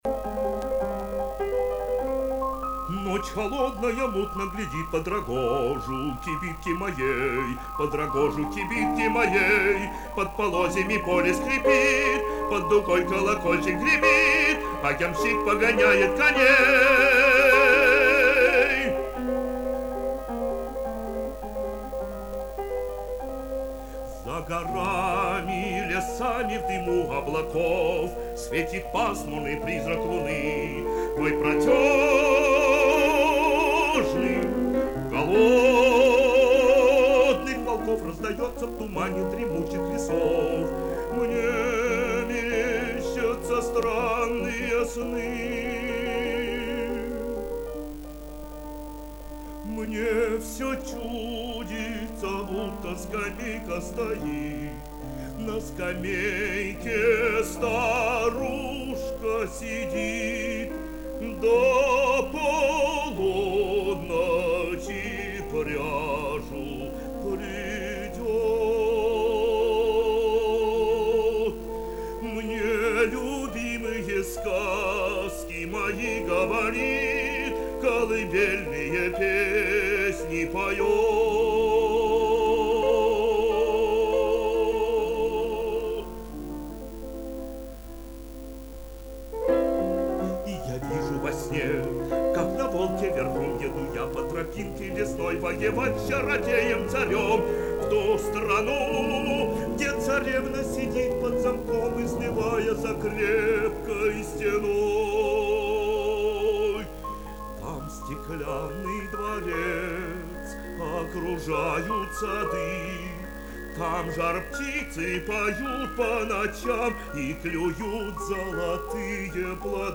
Прослушивание аудиозаписи романса «Зимний путь».